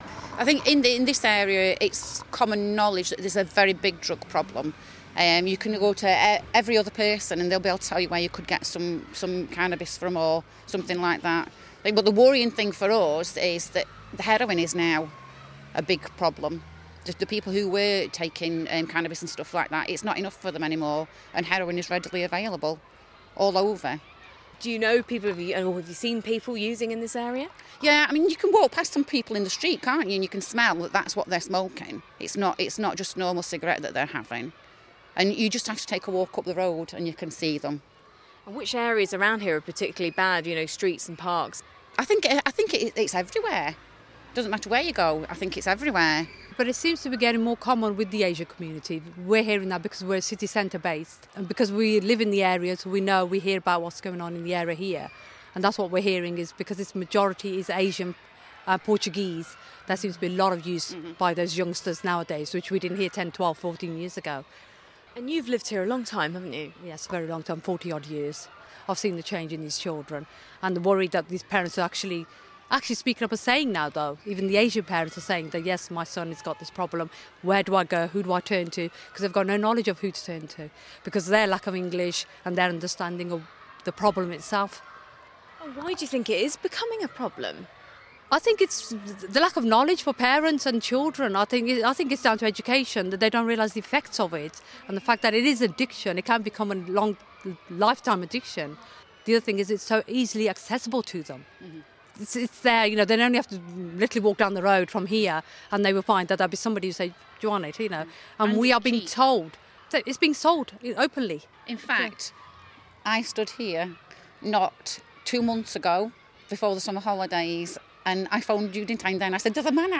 These are Mums in the Wyld's Lane area of Worcester.